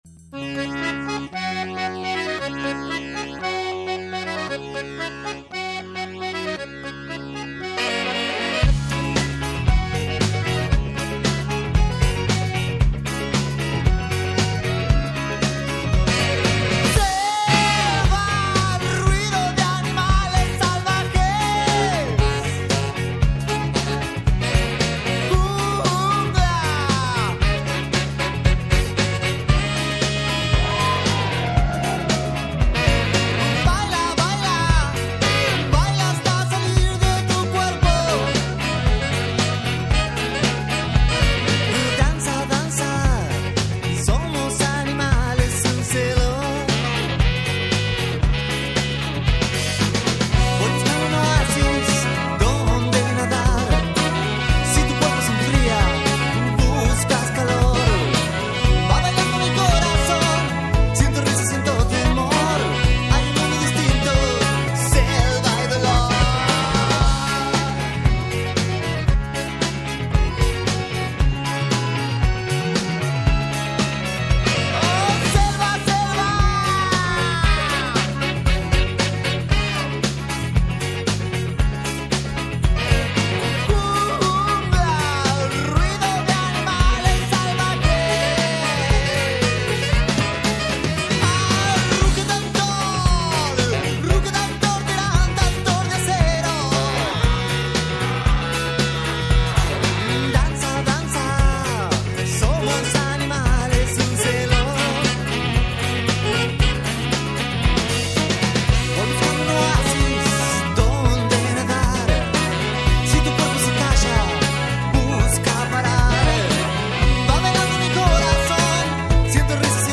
Carpeta: Rock argentino mp3